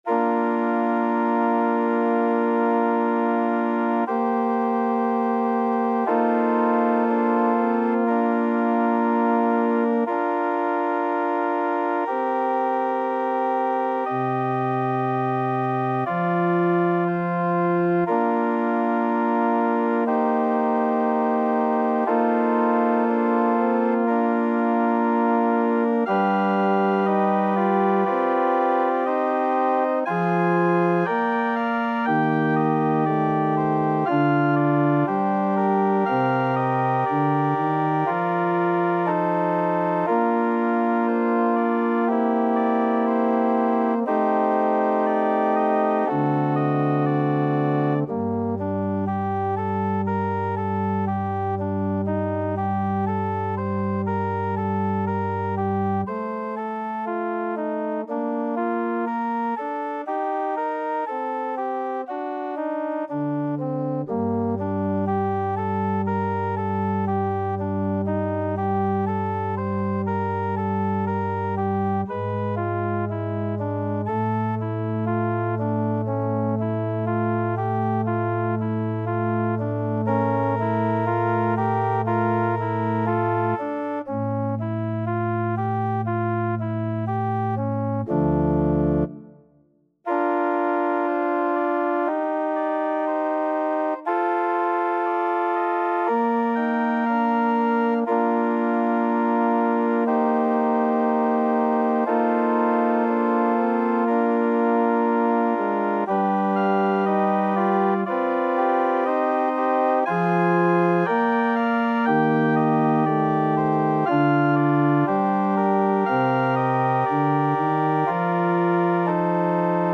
Play (or use space bar on your keyboard) Pause Music Playalong - Piano Accompaniment Playalong Band Accompaniment not yet available reset tempo print settings full screen
~ = 100 Andantino sempre legato =60 (View more music marked Andantino)
Classical (View more Classical French Horn Music)